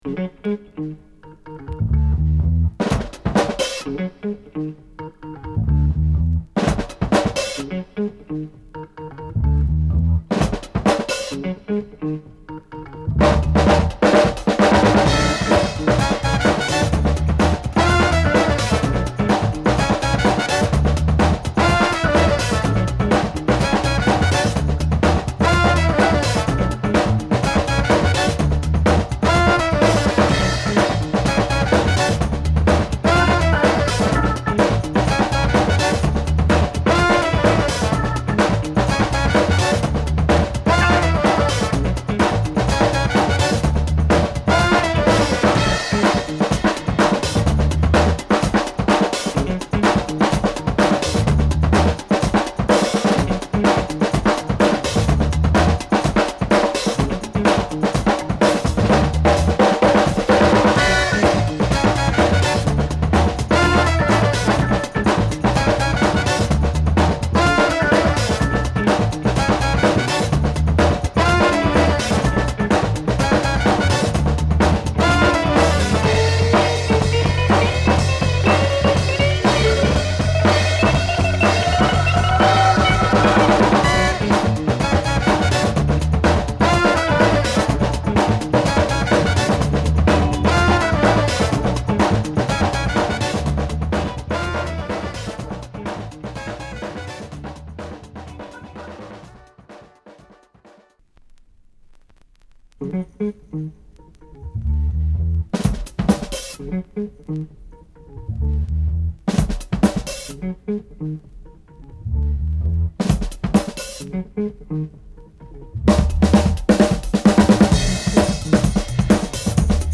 UKの現行ファンクバンド